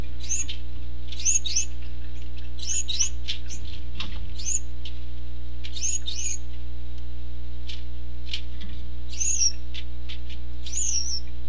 Audio clips were obtained from my personal breeding pairs.
Cock's Calling Noises (.mp3, .2 MB)
male_gould_calls.mp3